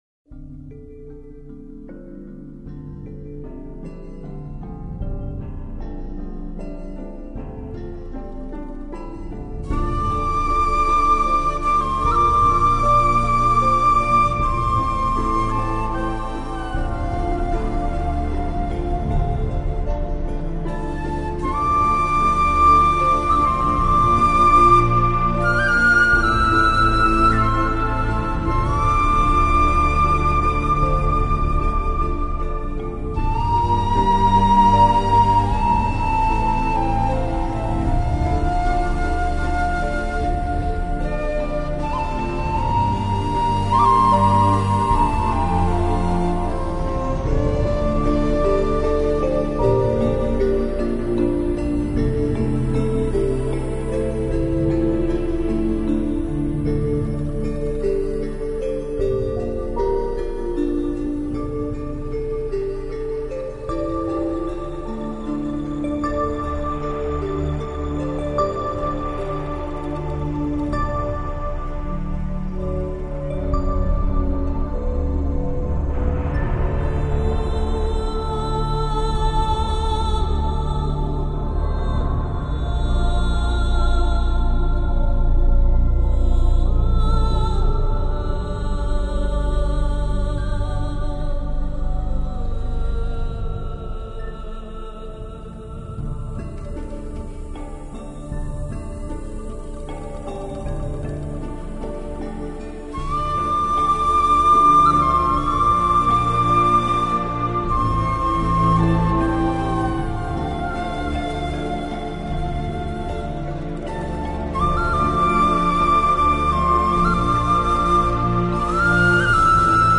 Enjoy, and try not to let it lull you to sleep.